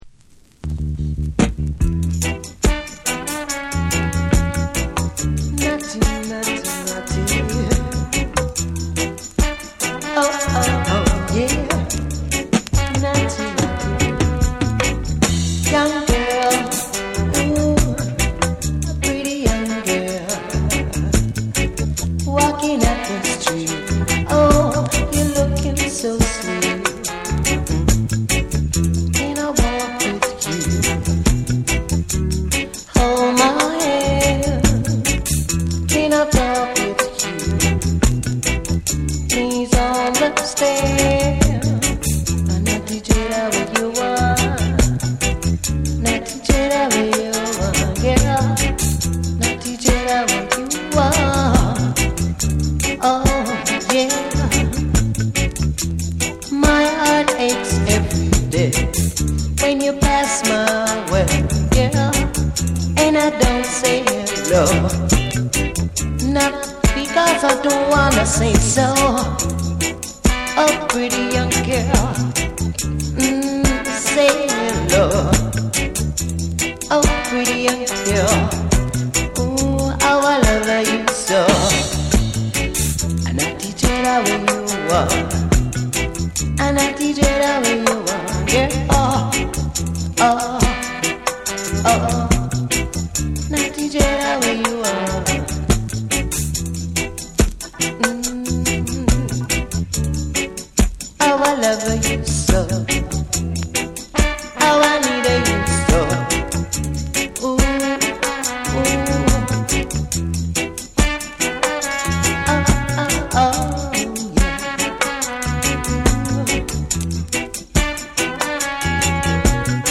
哀愁メロディが沁みる